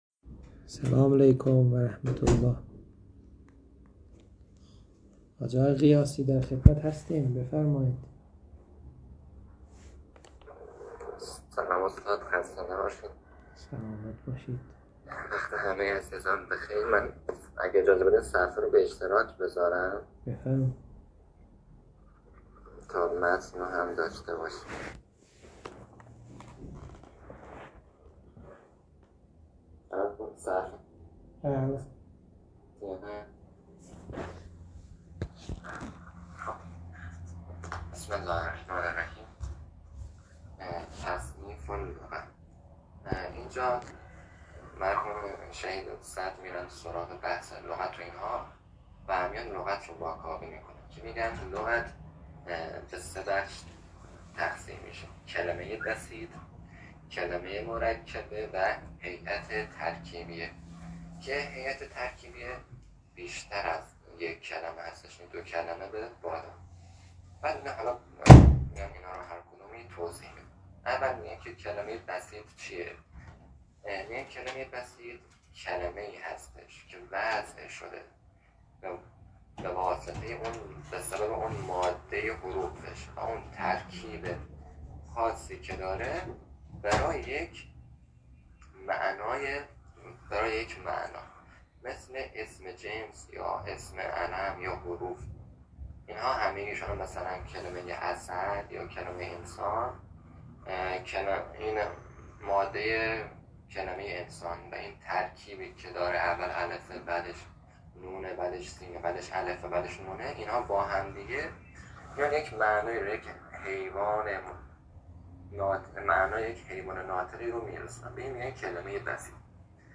تدریس كتاب حلقه ثانیه